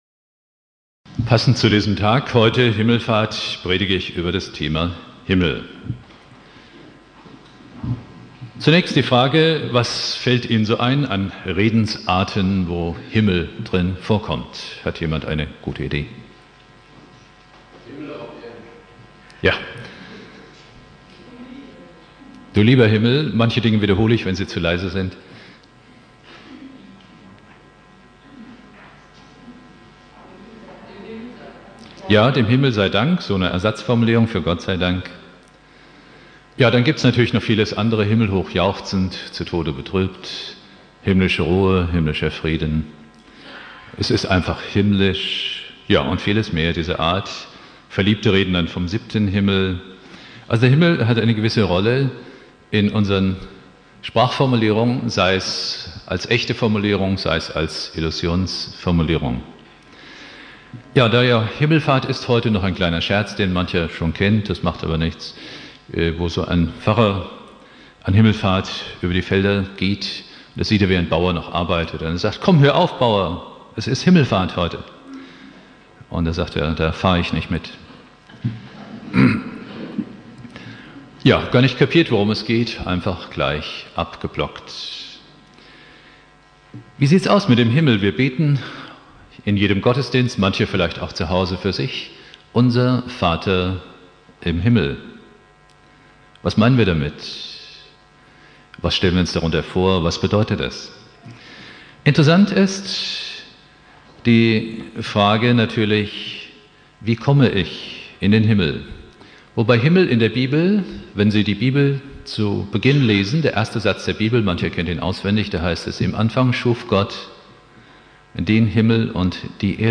Predigt
Christi Himmelfahrt Prediger